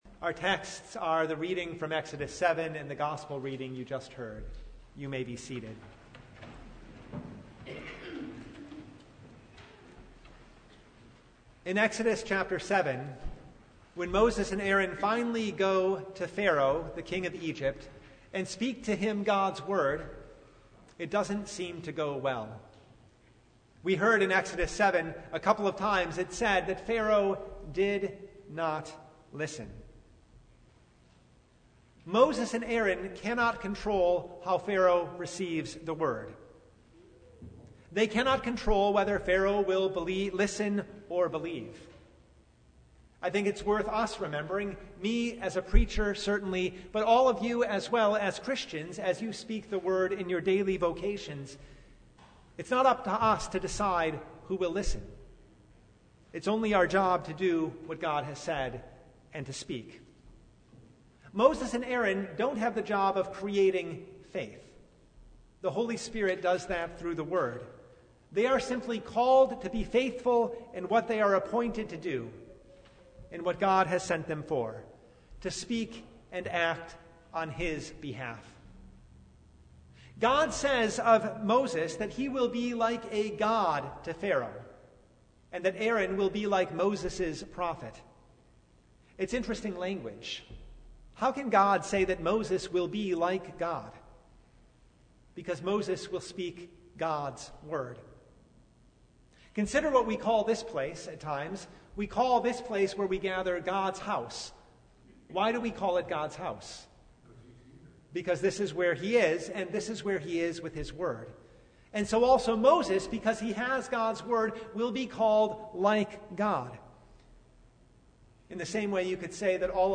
Passage: Exodus 7:1-25 Service Type: Lent Midweek Noon
Sermon Only